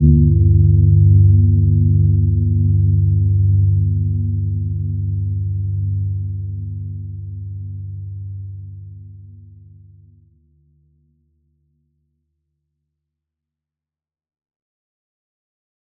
Gentle-Metallic-2-G2-p.wav